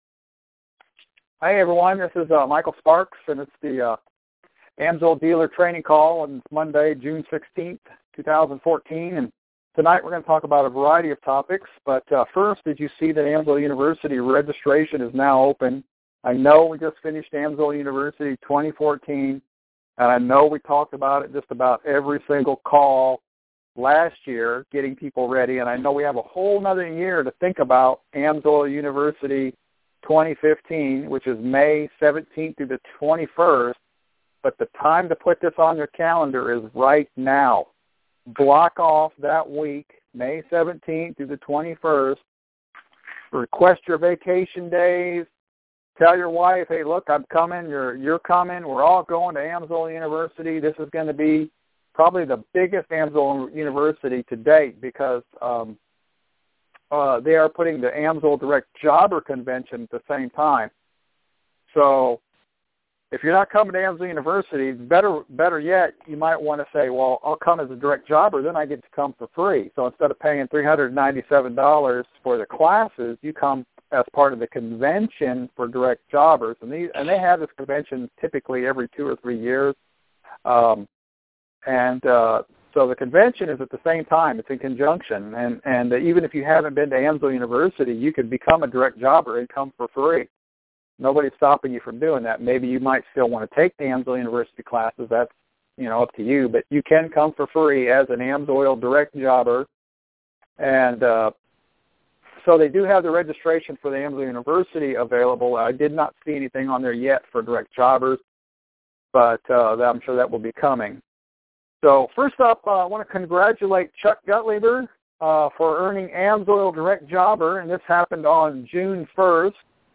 Our weekly AMSOIL Dealer training call. We talk about a variety of subjects this week, AMSOIL University 2015, New Direct Jobbers, AMSOIL Display Co-op and how to start an email newsletter